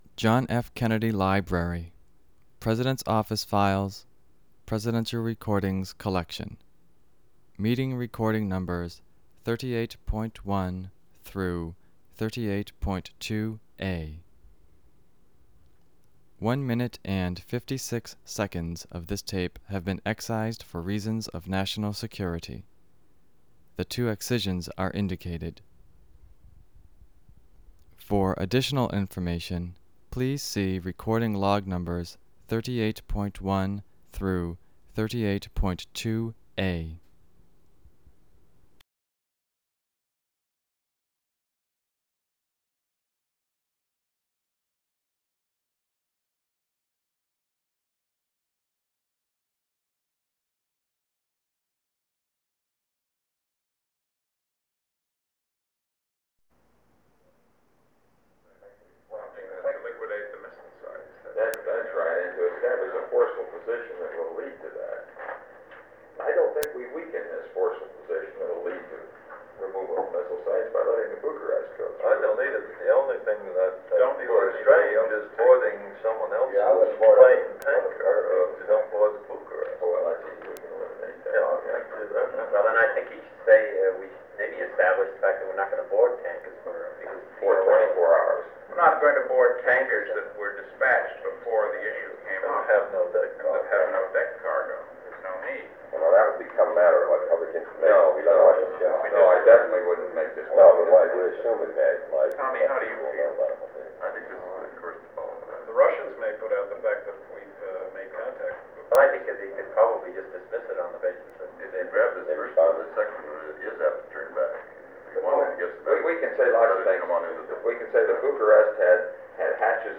Executive Committee Meeting of the National Security Council on the Cuban Missile Crisis (cont.)
Secret White House Tapes | John F. Kennedy Presidency Executive Committee Meeting of the National Security Council on the Cuban Missile Crisis (cont.)